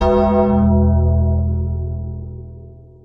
Undertaker Bell Sound Button - Botón de Efecto Sonoro